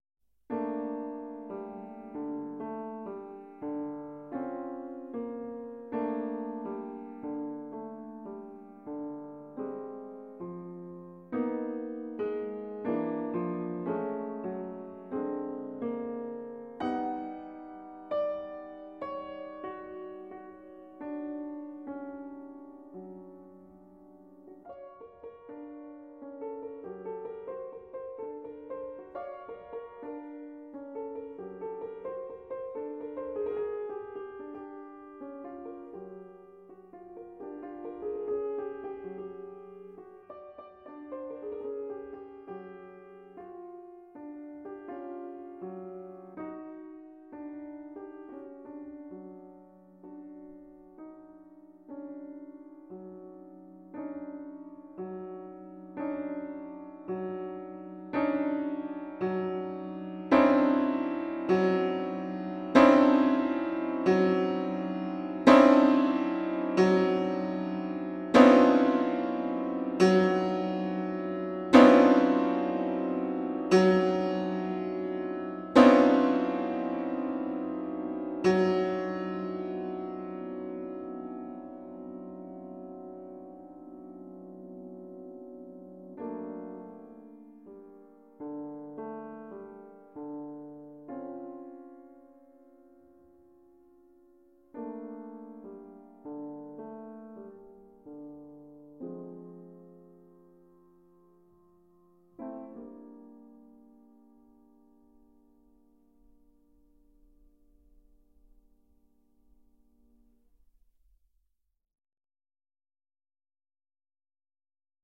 Kūriniai fortepijonui / Piano Works
fortepijonas / piano